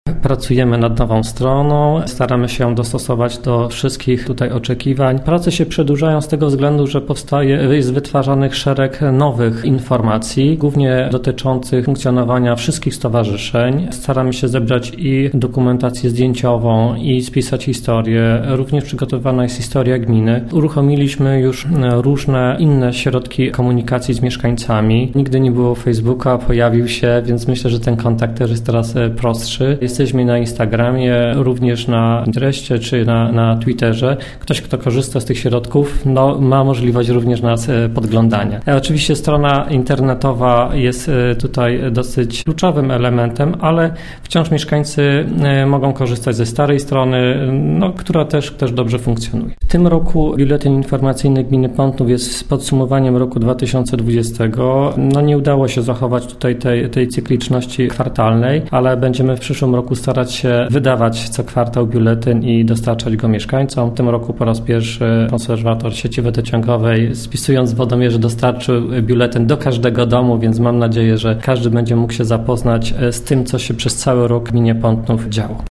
– mówił wójt gminy Pątnów, Jacek Olczyk.